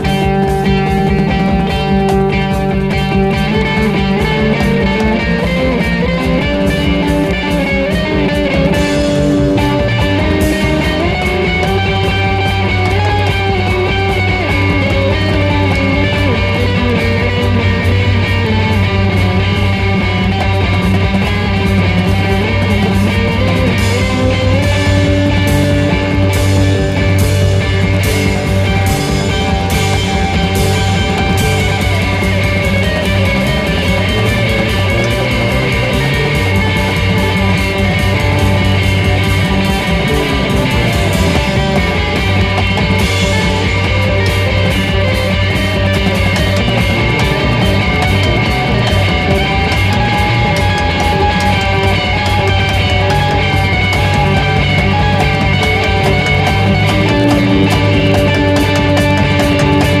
ROCK / 70'S / SWAMP ROCK / PSYCHEDELIC ROCK / COUNTRY ROCK
71年サイケデリック風味ハード・カントリー・ロック唯一作！